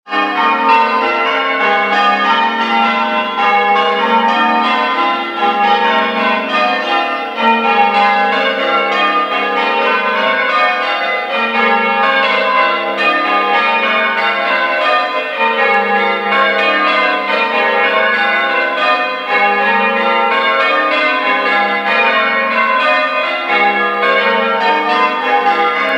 A recording of the bells.
There are 6 bells at Old Brampton.
They are rung in the traditionally English style of full-circle ringing with a rope round a wheel.
Bells.mp3